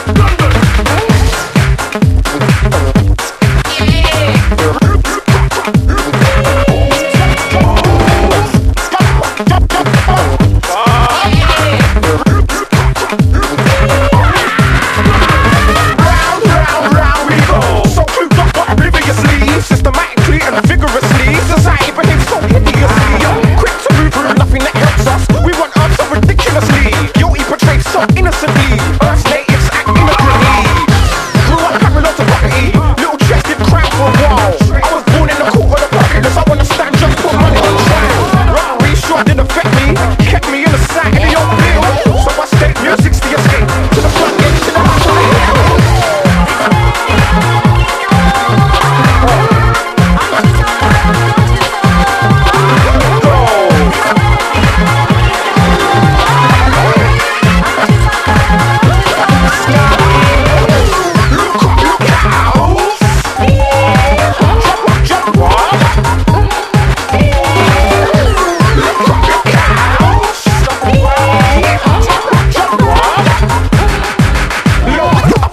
EASY LISTENING / VOCAL / CHORUS / SOFT ROCK